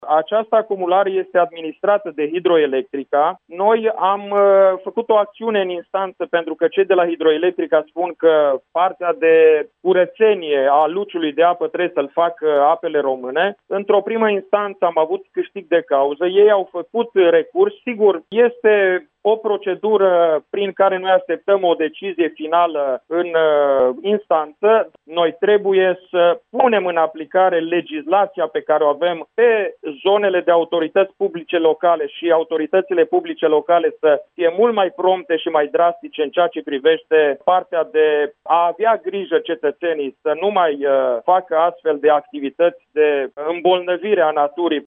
Ministrul Apelor şi Pădurilor, Ioan Deneş, a mai declarat, pentru Radio Iaşi, că există o acţiune în instanţa pentru tragerea la răspundere a celor care ar fi trebuit să se ocupe de ecologizarea lacului de acumulare Izvorul Muntelui, din judeţul Neamţ.